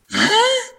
mobs_pig.ogg